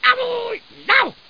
00726_Sound_yell.mp3